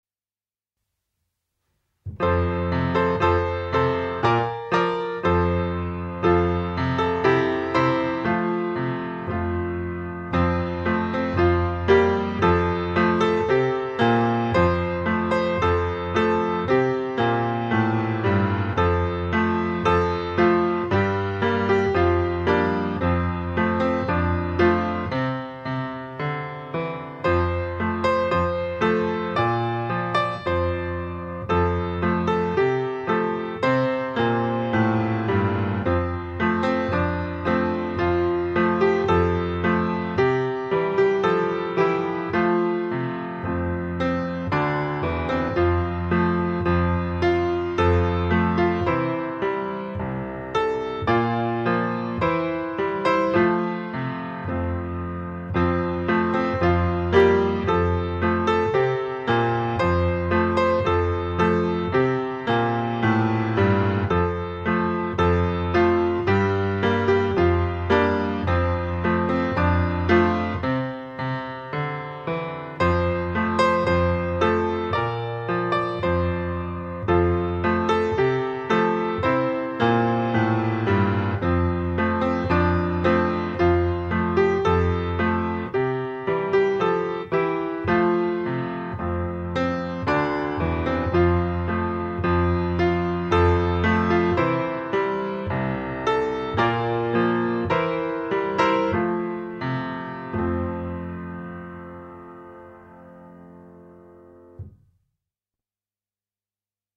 関小校歌（伴奏のみ）（注意）クリックすると校歌が流れます。